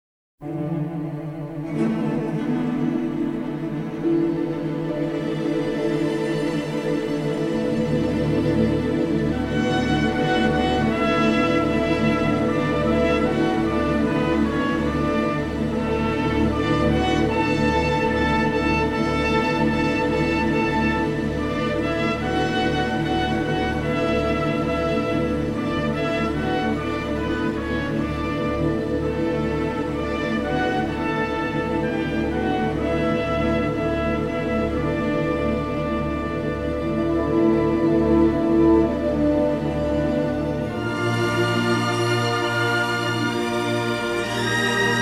and he responded with a gorgeous, symphonic score.
stereo LP configurations